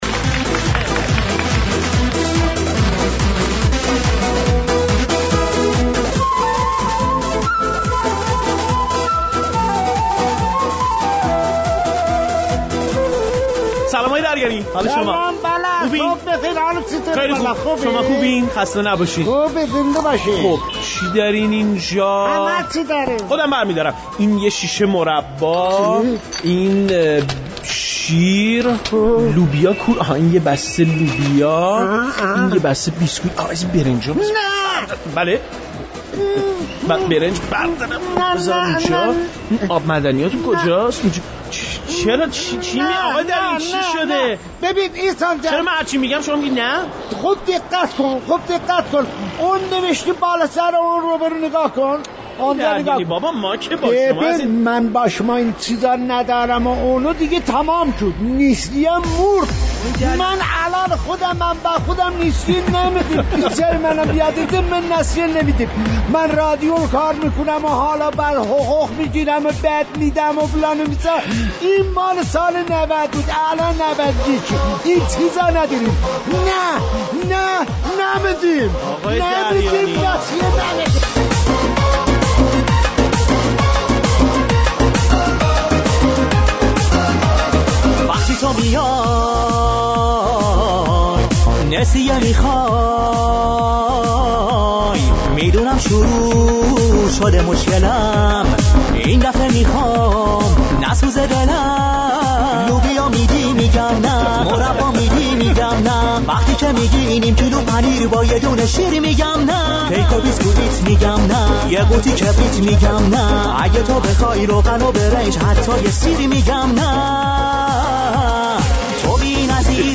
برنامه طنز رادیو ایران